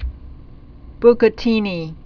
(bkə-tēnē)